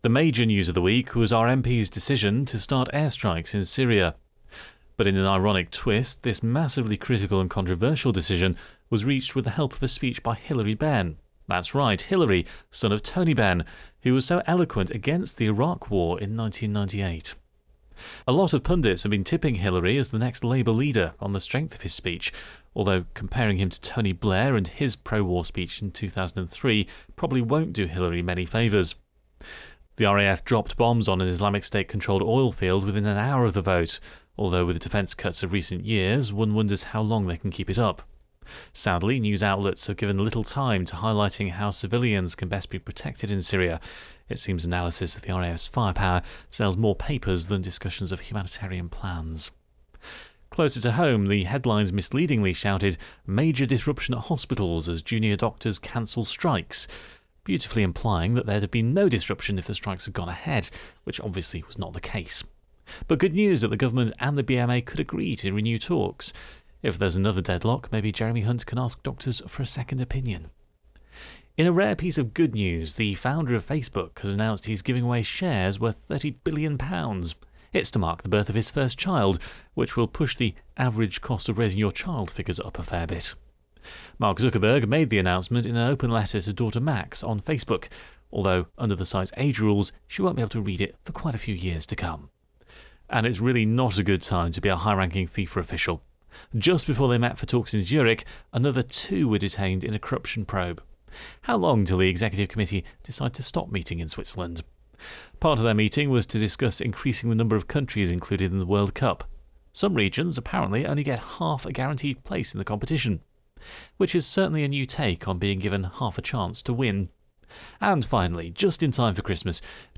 This Week’s Weekly News Round Up Is Here…